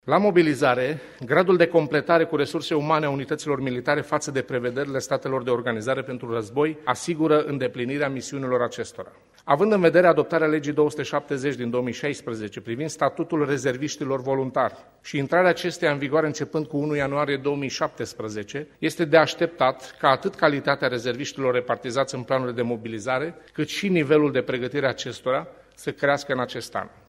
Ministrul Apărării Naţionale, Gabriel Leş: